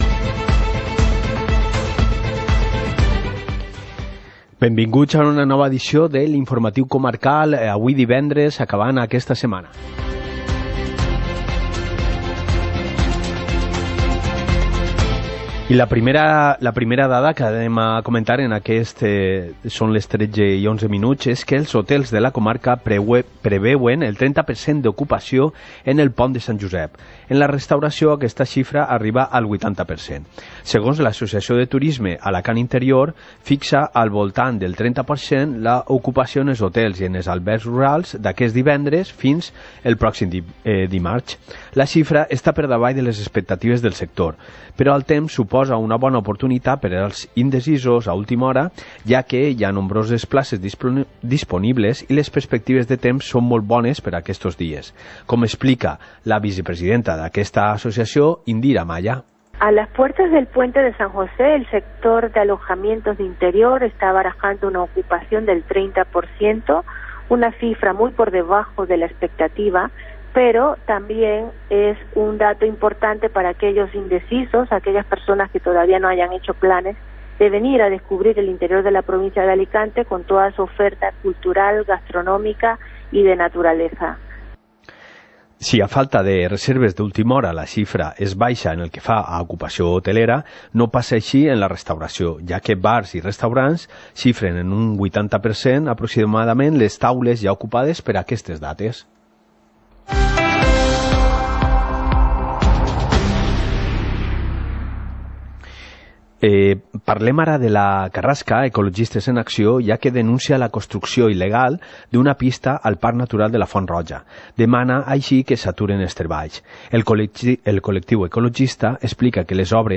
Informativo comarcal - viernes, 15 de marzo de 2019